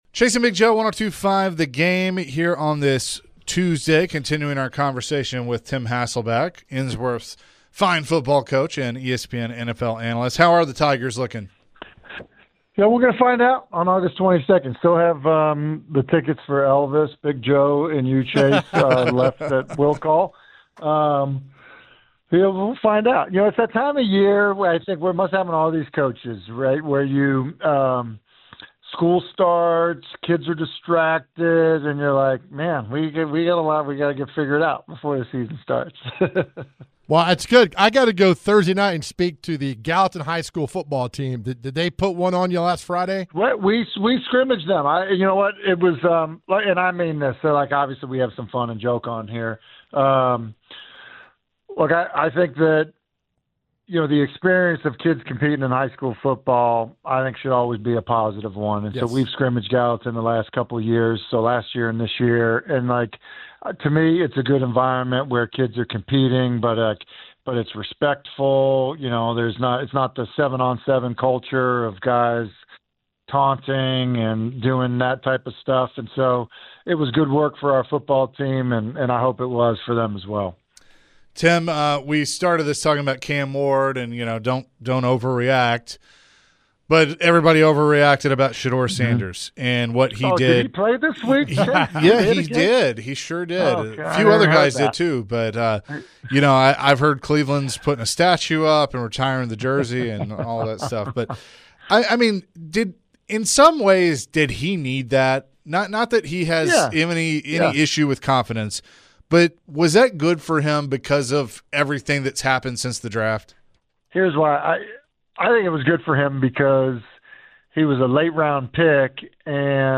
ESPN NFL Analyst Tim Hasselbeck joined the show and shared his thoughts on Cam Wards preseason debut. Also, Tim added his comments about Sheduer Sanders as well. Can Sanders make the Browns roster?